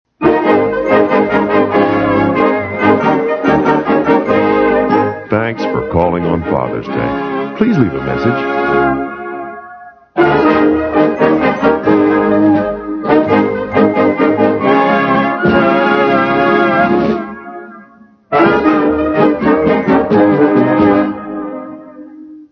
Phonies Holiday Telephone Answering Machine Messages